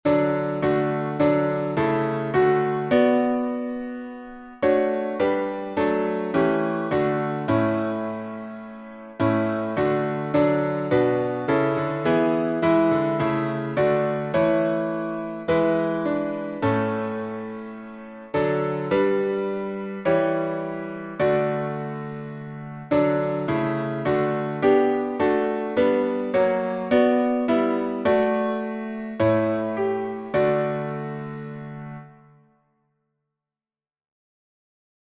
Key signature: D major (2 sharps) Time signature: 4/4
Organ Performance